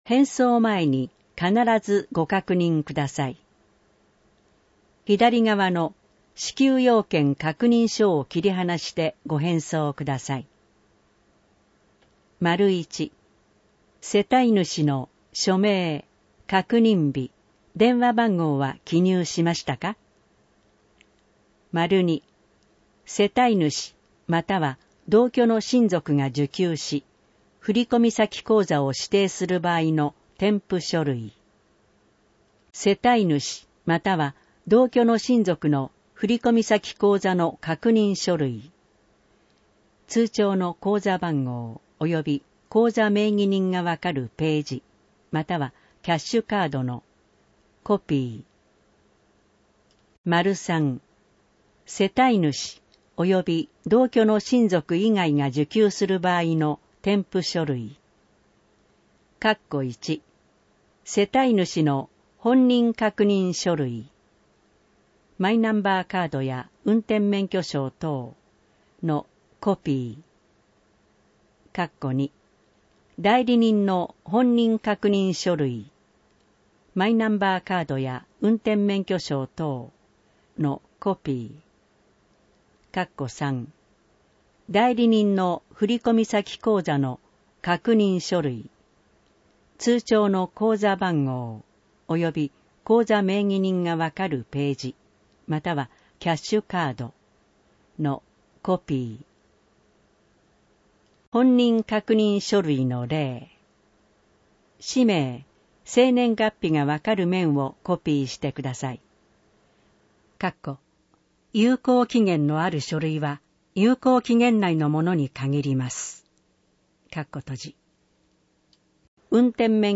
なお、この音声版は、障がい者総合サポートセンター声の図書室で製作したCDを再生したものです。